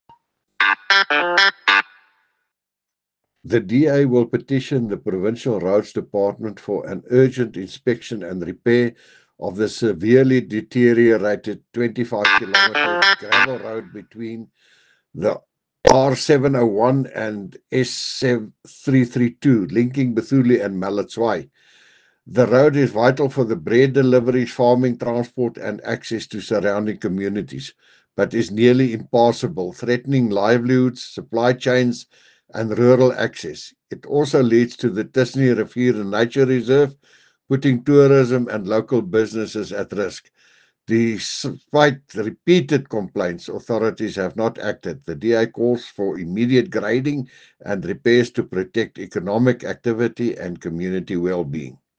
Afrikaans soundbites by Cllr Jacques van Rensburg and